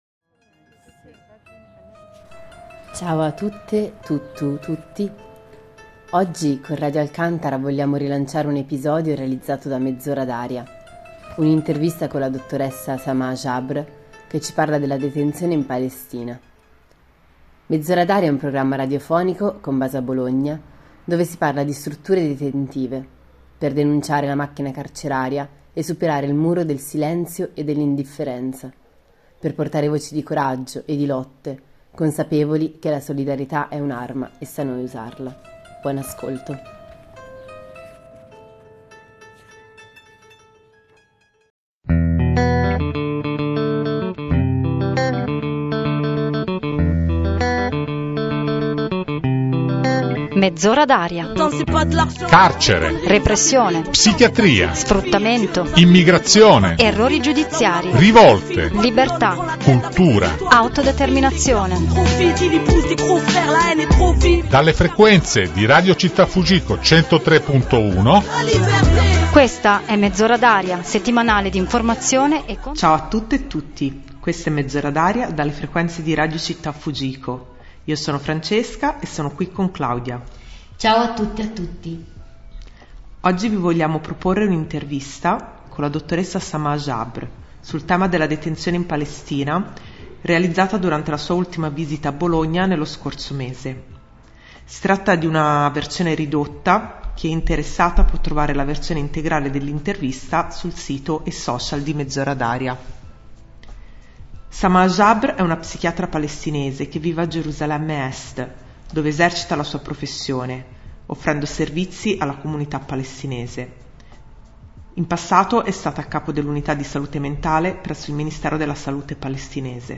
Oggi ascoltiamo le sue parole in un'intervista che si fa testimonianza, in una testimonianza che si fa denuncia. Con voce lucida e occhio clinico ripercorre il dramma di un popolo che resiste sotto un'occupazione sanguinaria e ne ricostruisce le ferite.